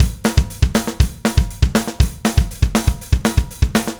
Power Pop Punk Drums 01c.wav